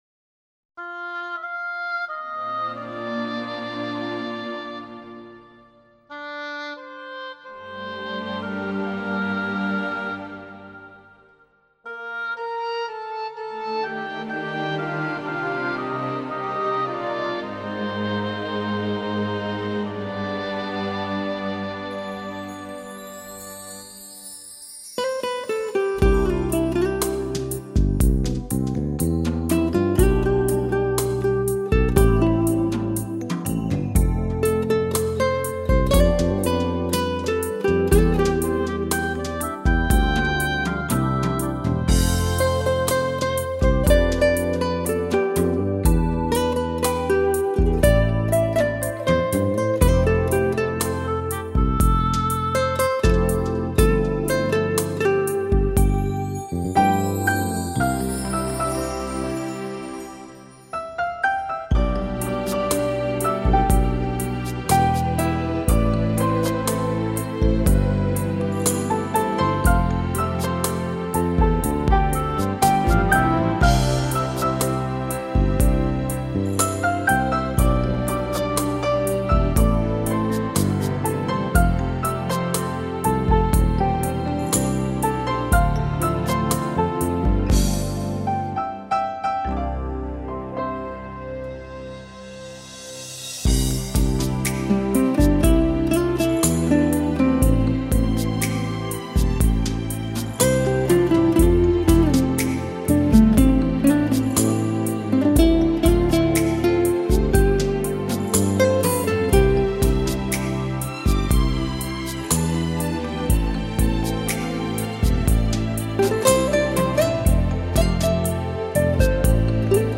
音乐，文字，图片。